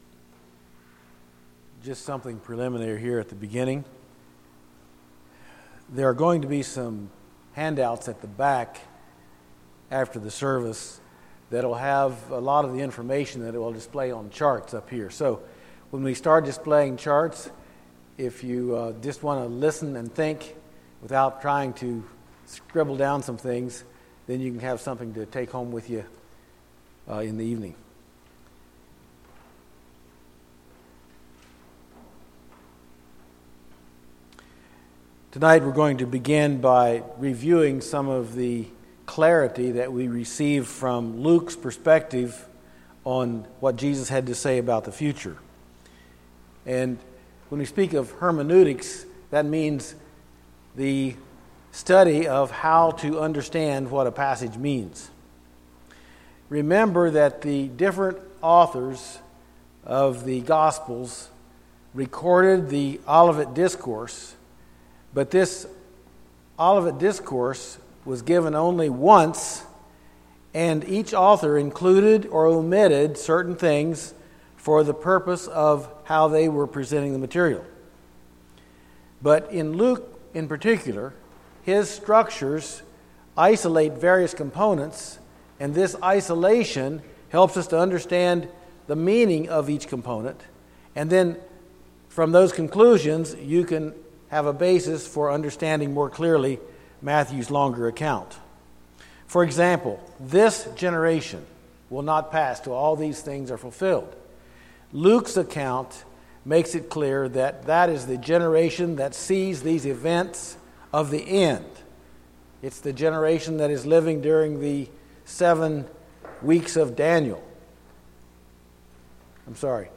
Bible Teaching Service Type: Saturday Evening %todo_render% « Luke